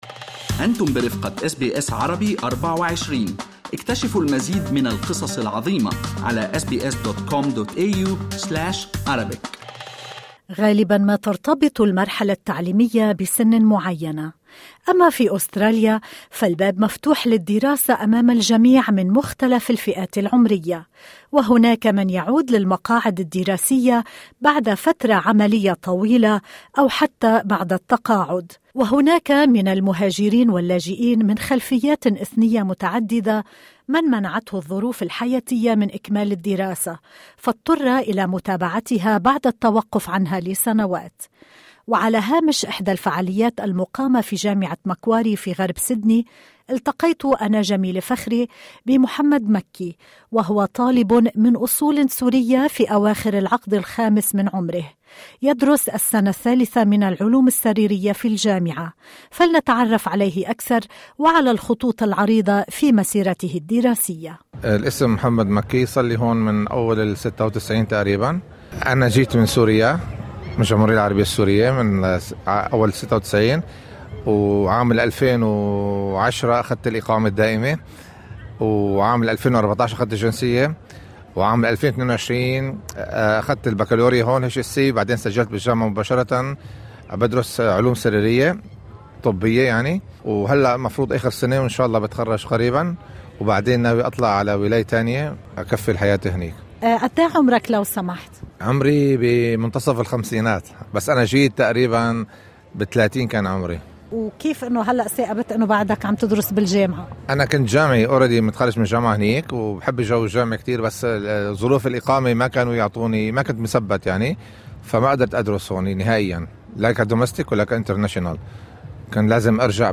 SBS covering an event at Macquarie university in Western Sydney.